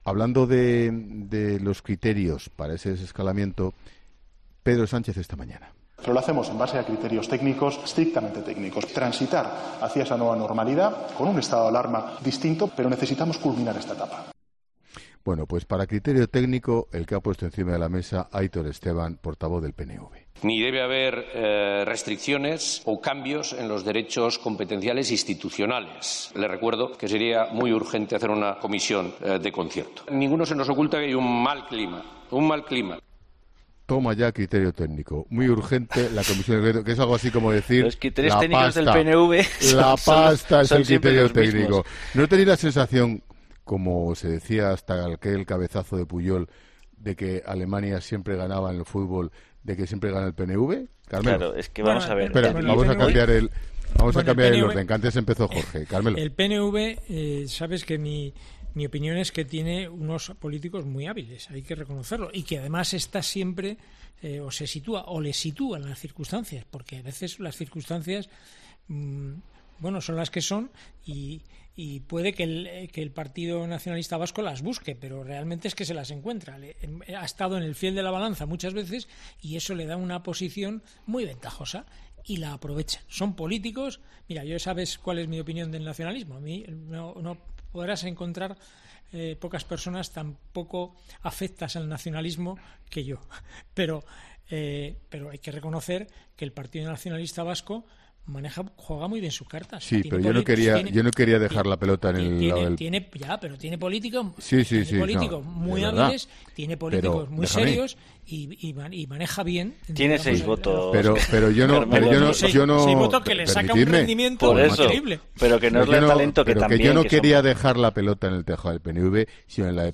Ángel Expósito ha aprovechado un momento de la tertulia de ‘La Linterna’ de este miércoles para comentar dos momentos de la sesión de control al Gobierno en el Congreso.
“Para criterio técnico, el que ha puesto encima de la mesa Aitor Esteban, portavoz del PNV”, ha añadido Expósito antes de que el sonido del político nacionalista vasco entrase en antena.